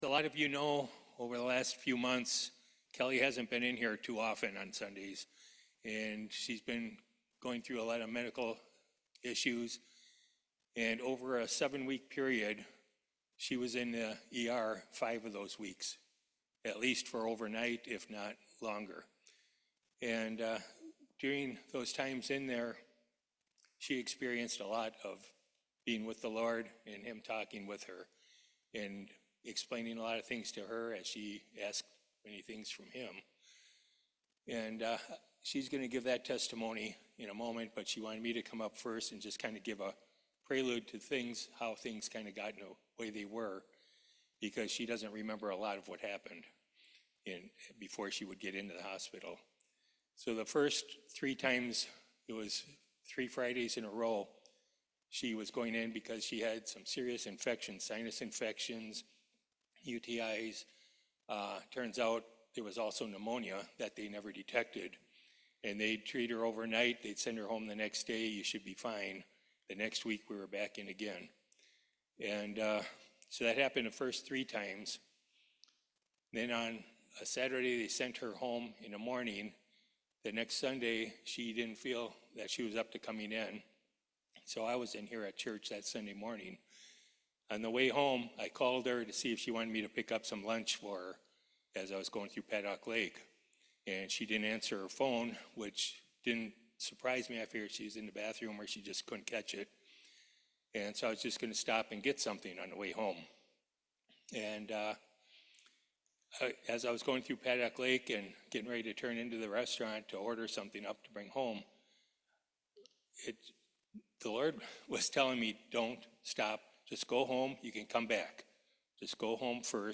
Testimony Passage
Isaiah 60:1-5 Service Type: Main Service God will get you through it all.